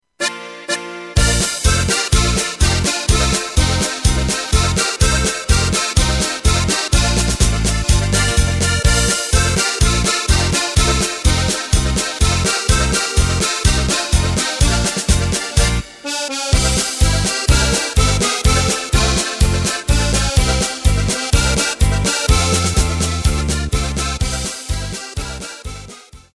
Takt:          2/4
Tempo:         125.00
Tonart:            Bb
Wiener-Lied!
Playback mp3 Mit Drums